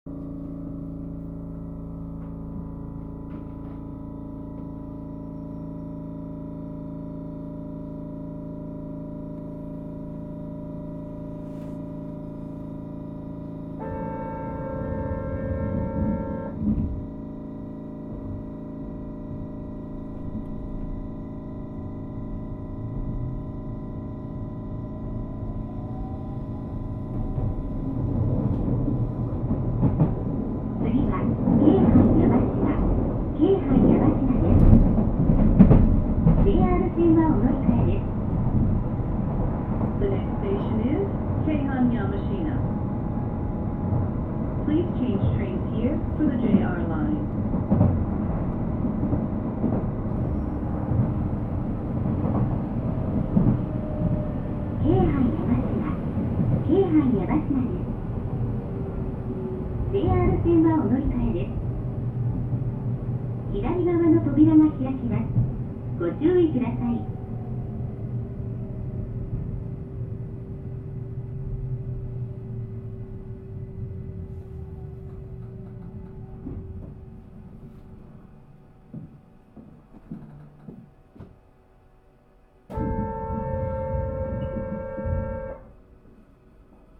走行音
録音区間：四宮～京阪山科(お持ち帰り)